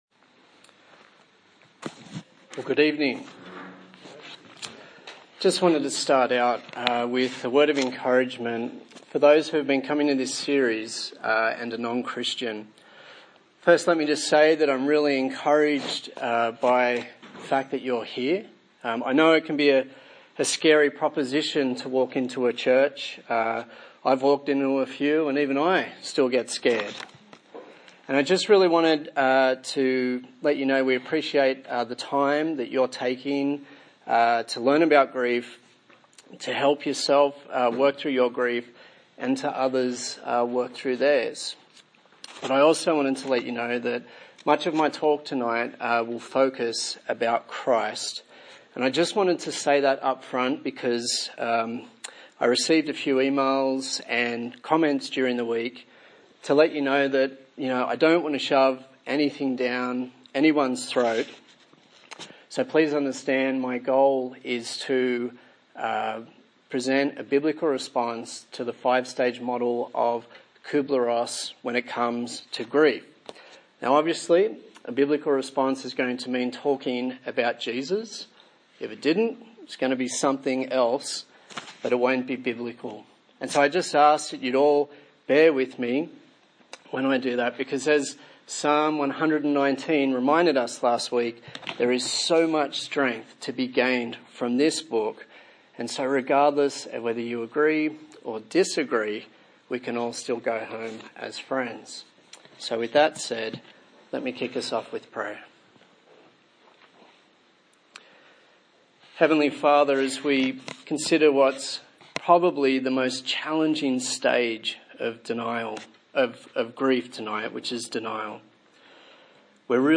A sermon in the series on Grief - Redeeming Broken Glass